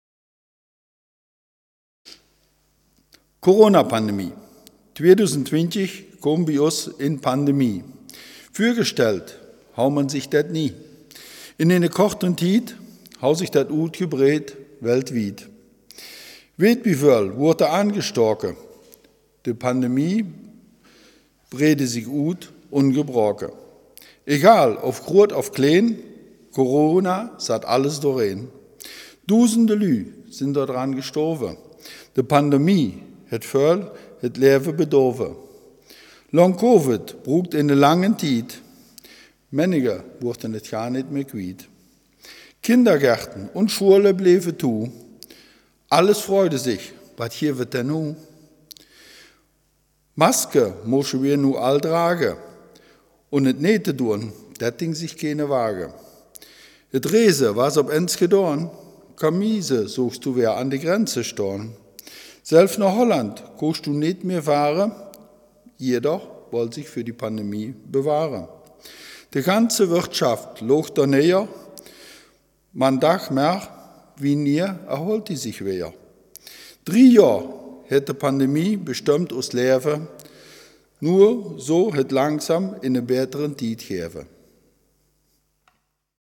Selfkant-Platt
aus der Sprachregion Selfkant-Platt in der Rubrik Gefühlswelt, negativ, Startseite